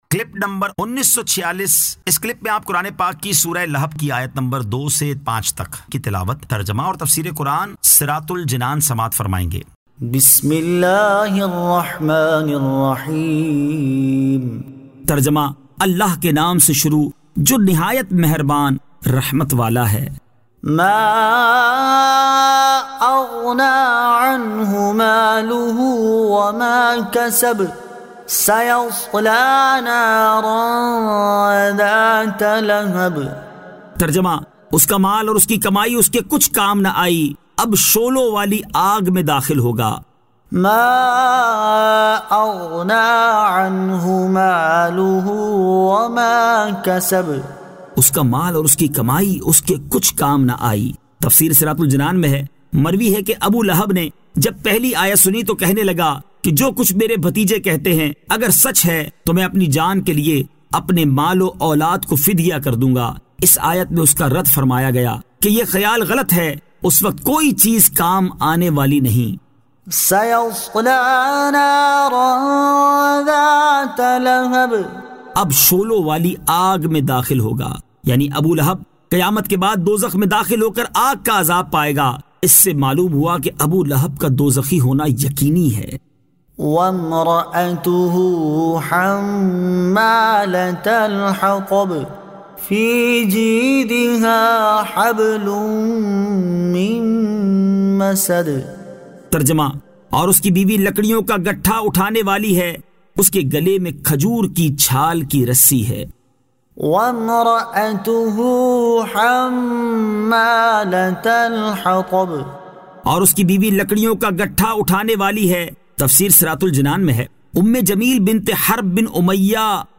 Surah Al- Lahab 02 To 05 Tilawat , Tarjama , Tafseer